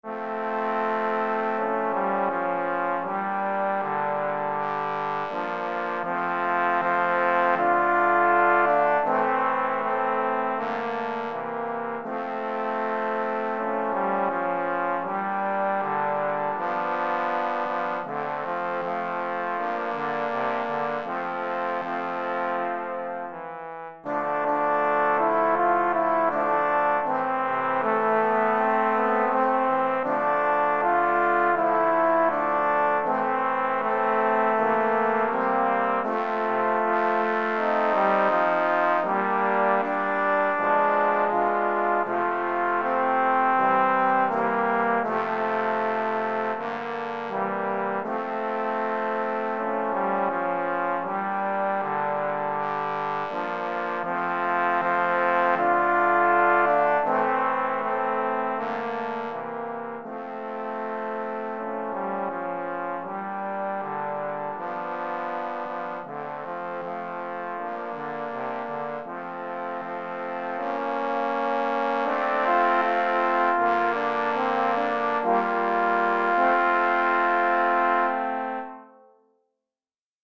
Recueil pour Trombone - 3 Trombones et Trombone Basse